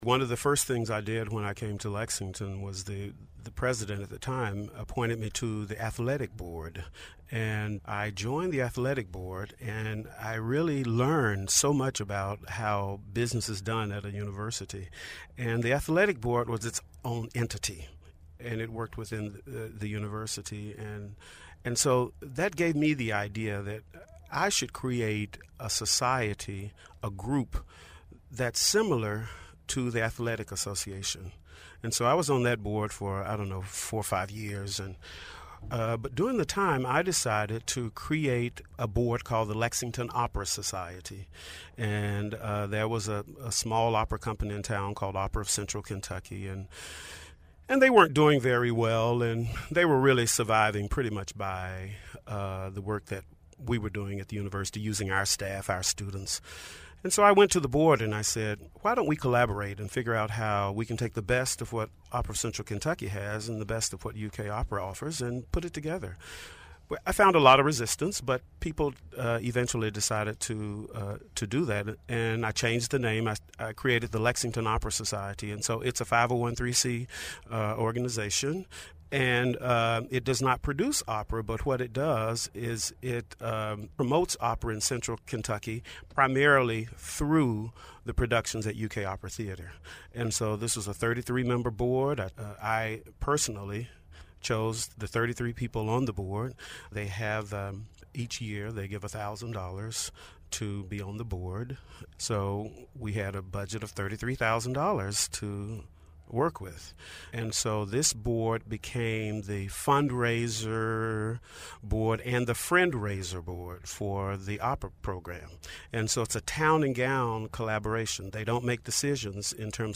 Transcript of conversation